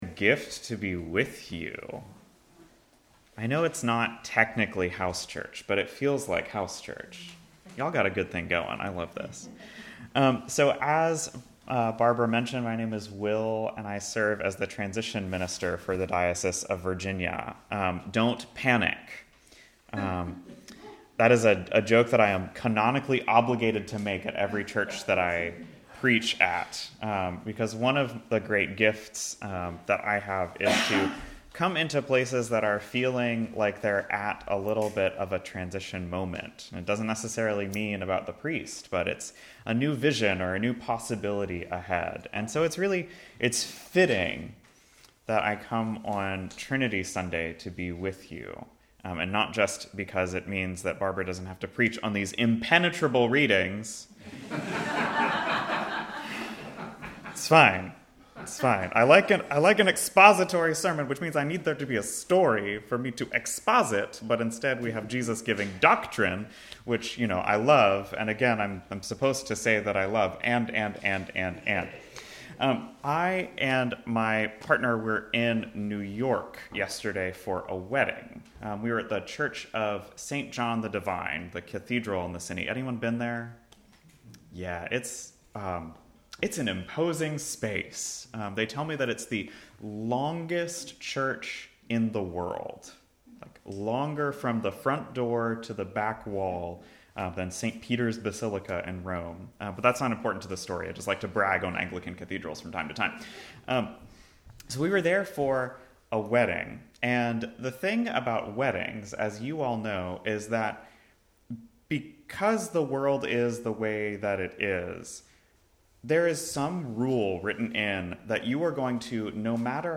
Sermon June 15, 2025